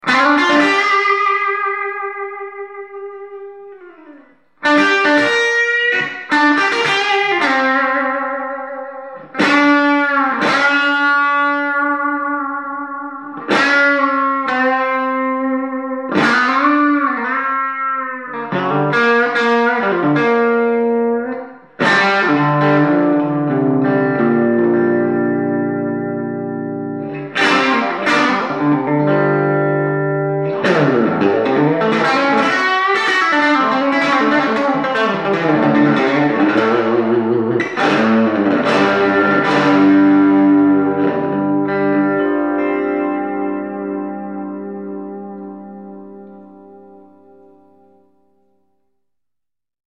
Room Nocastor Tele Low Gain 1   .56